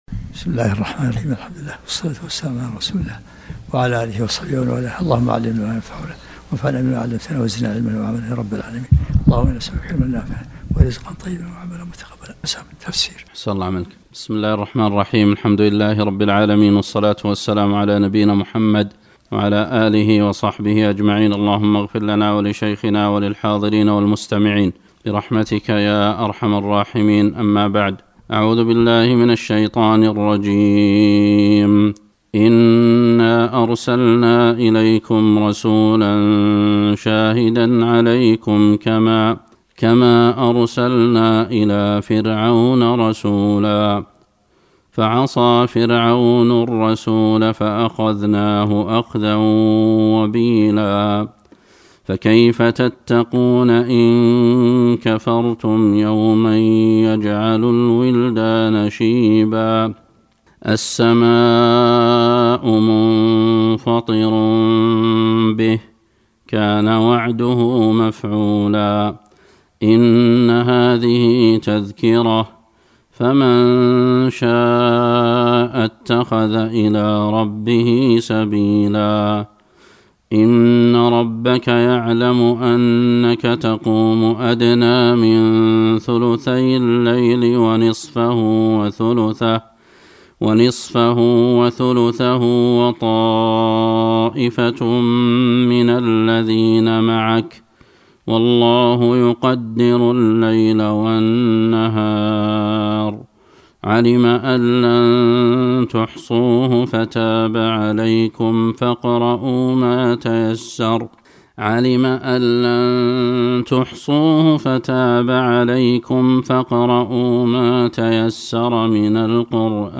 الدروس العلمية
تفسير مباشر للآيات